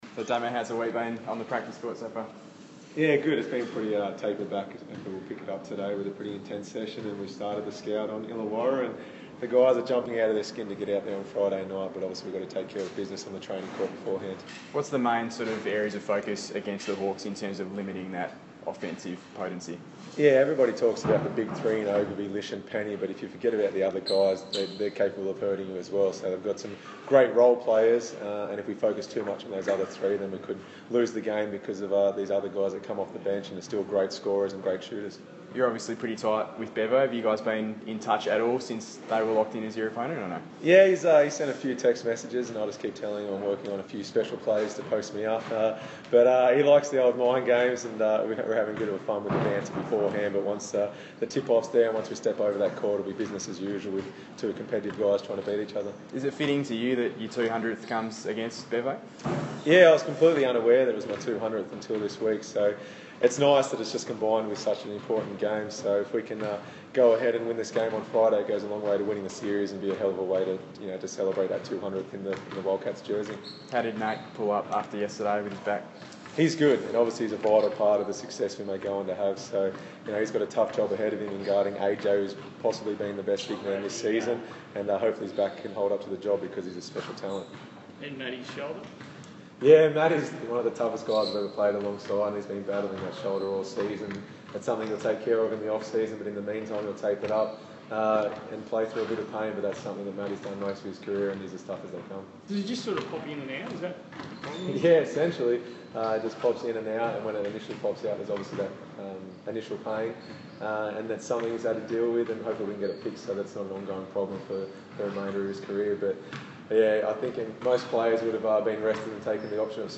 Damian Martin press conference - 17 February 2016
Captain Damian Martin speaks to the media ahead of his 200th Perth Wildcats game on Friday night.